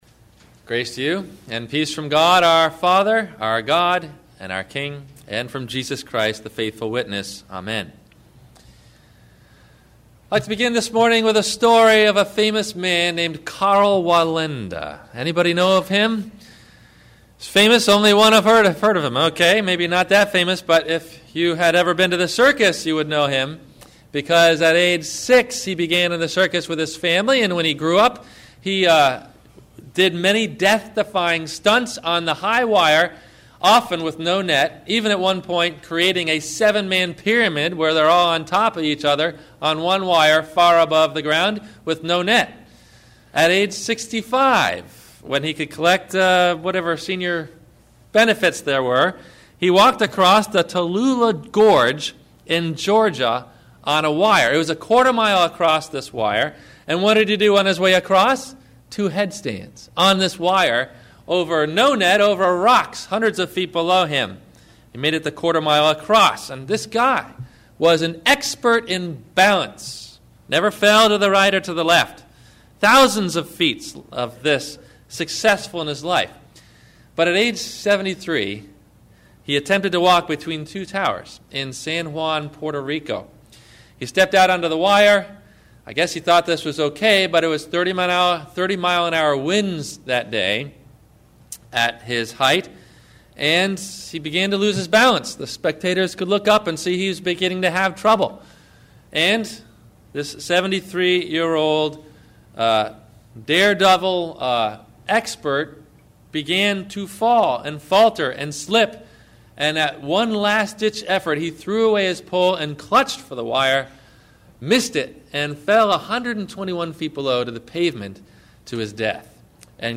Understanding – Faith and Works – Sermon – January 18 2009